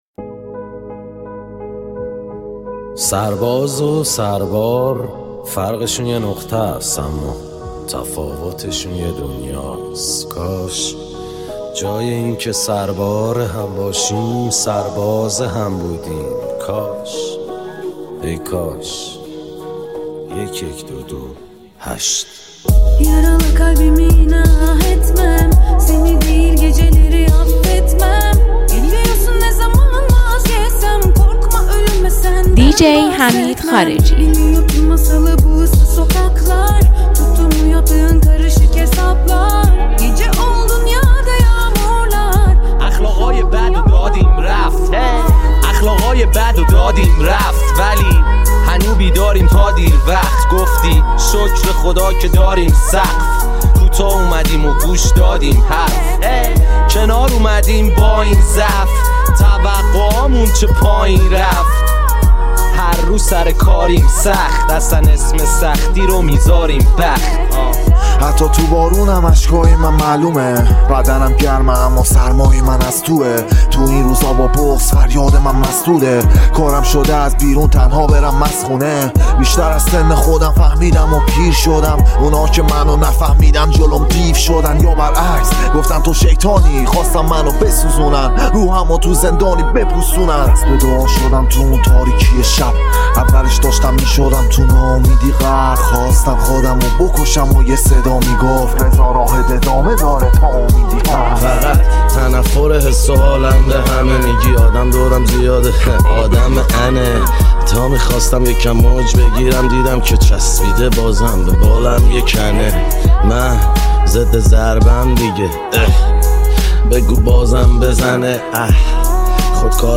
یه میکس ترکیبی متفاوت و بی‌نظیر
تلفیقی از بهترین آهنگ‌های رپ فارسی